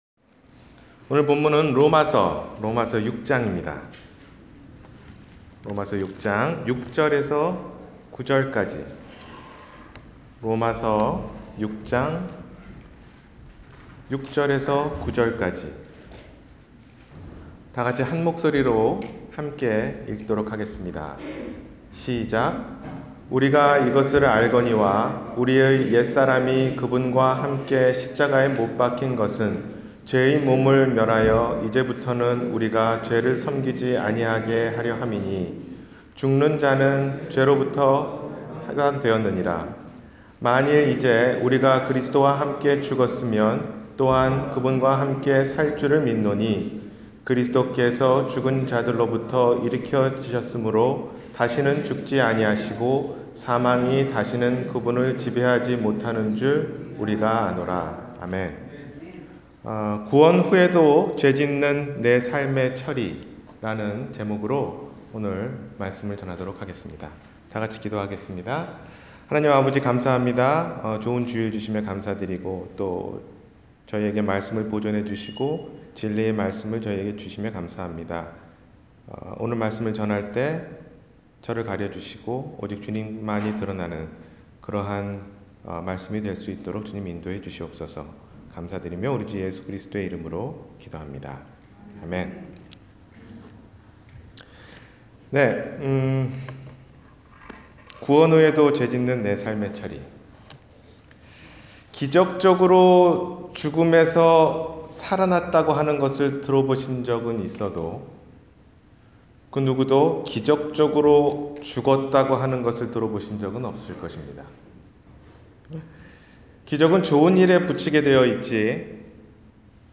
구원후 죄짓는 삶의 처리-주일설교